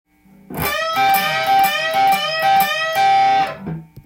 オリジナルギターtab譜　key Am
フレーズは全てペンタトニックスケールで構成されています。
①のフレーズは、定番中の定番チョーキング＆小指　の繰り返しフレーズです。